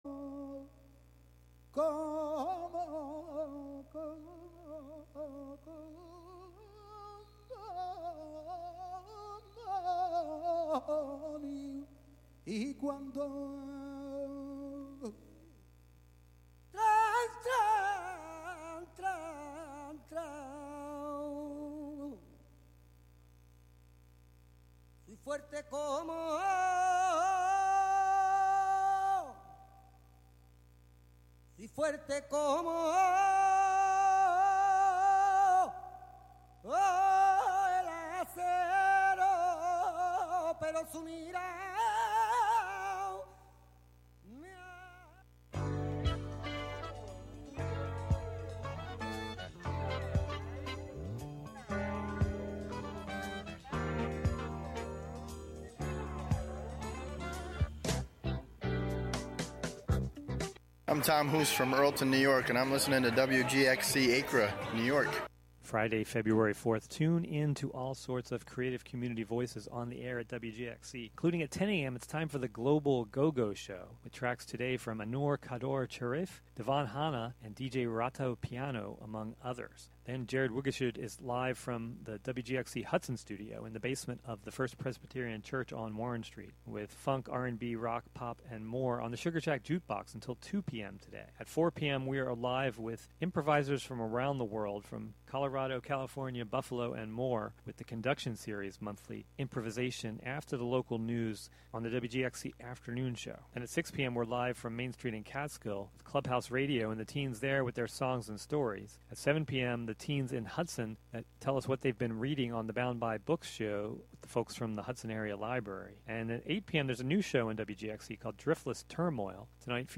After the local news at noon, a two-hour music program featuring jazz and often other related genres, such as R&B, fusion, blues, and rock n' roll.